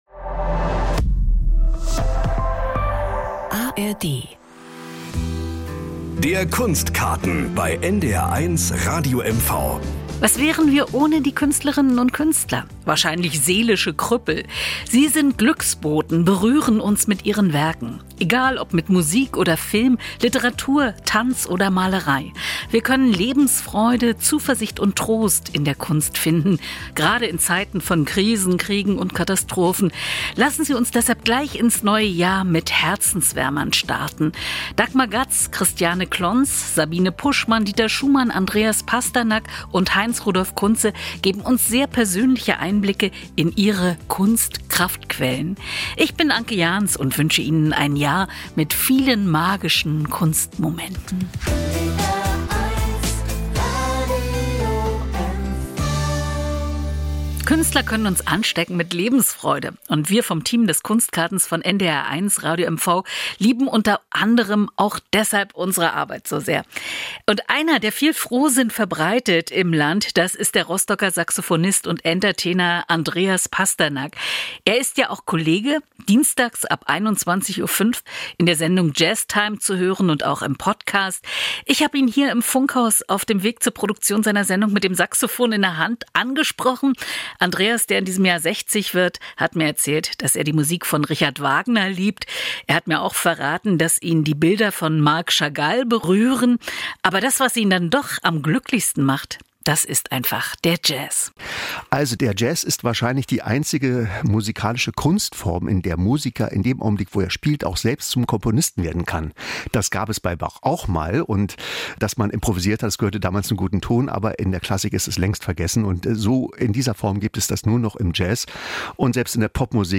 Nachrichten aus Mecklenburg-Vorpommern - 06.04.2024